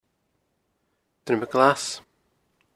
Pronounced by the informant on the recording as Drim-muh Glas